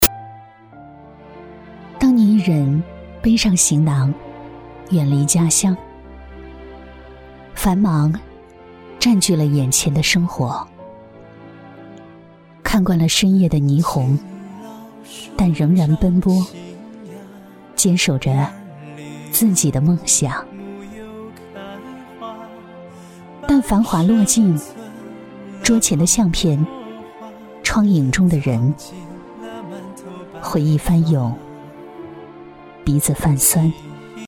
• 女S114 国语 女声 个人独白 时间流逝-内心独白-深情 大气 沉稳|亲切甜美